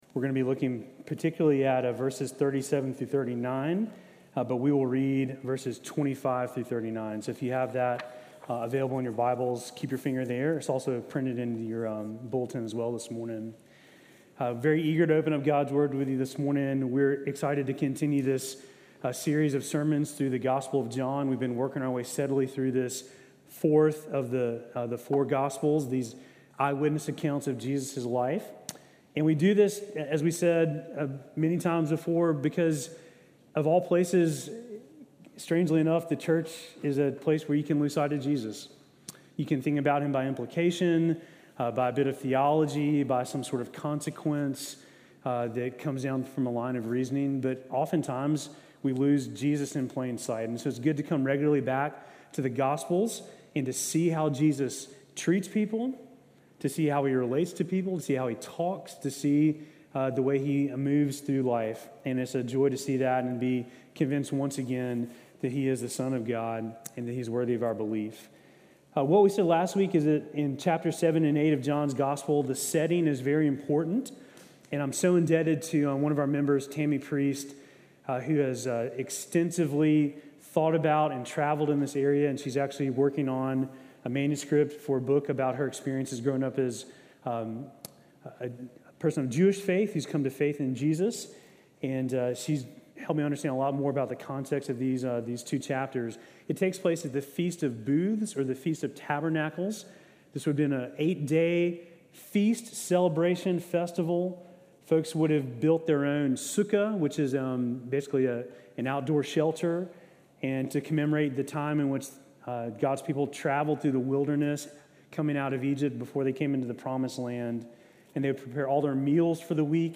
Sermon from August 24